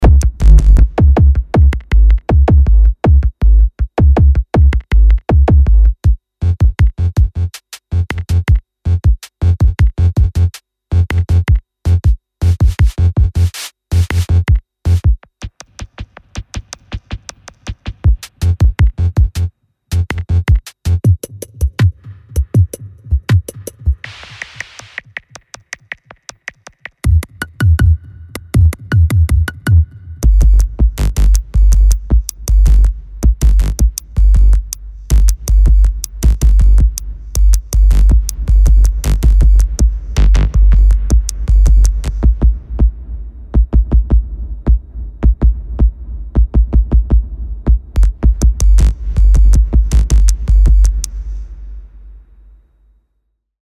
PERCUSSIVE TOOLS
An unique way to create electronic beats with endless rhythmic variations.